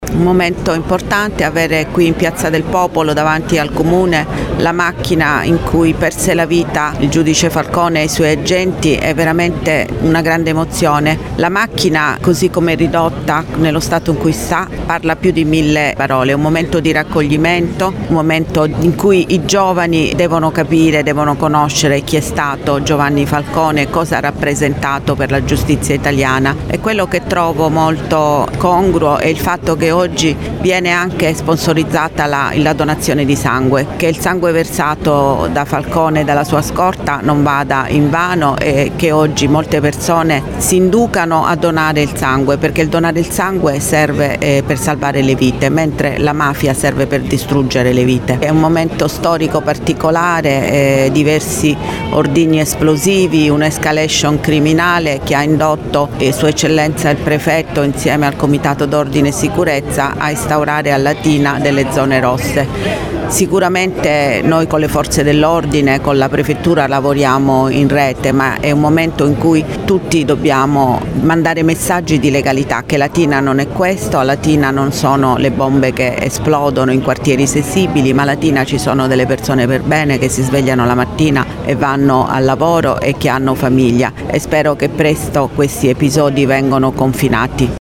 Si è tenuta questa mattina in Piazza del Popolo a Latina la cerimonia in occasione dello svelamento della teca della “Quarto Savona 15” l’auto della scorta del giudice Giovanni Falcone, distrutta nella strage di Capaci il 23 maggio 1992 in cui persero la vita oltre al magistrato e alla moglie Francesca Morvillo anche i tre agenti della scorta, Antonio Montinaro, Rocco Dicillo e Vito Schifani.
Il valore simbolico della memoria e l’importanza di parlare di legalità con i giovani dalle voci del Prefetto Vittoria Ciaramella e del Sindaco Matilde Celentano, anche in relazione al delicato momento storico in cui versa la città di Latina:
sindaco-qs15.mp3